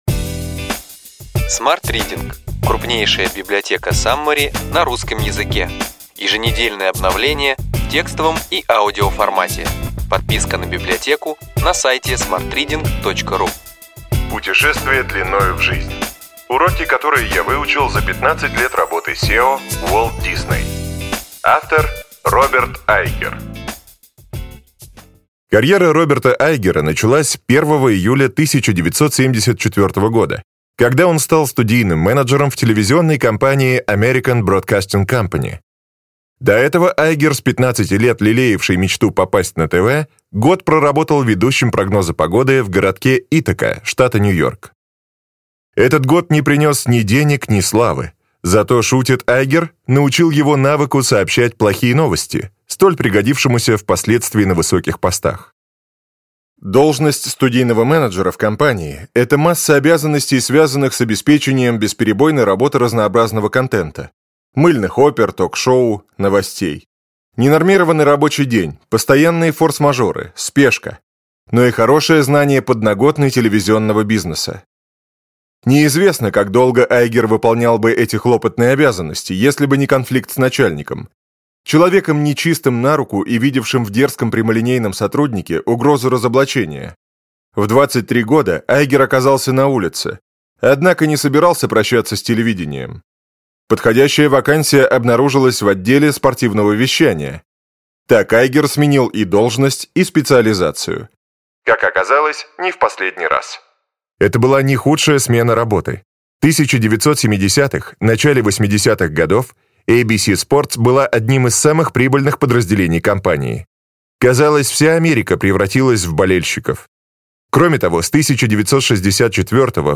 Аудиокнига Ключевые идеи книги: Путешествие длиною в жизнь. Уроки, которые я выучил за 15 лет работы CEO Walt Disney. Роберт Айгер | Библиотека аудиокниг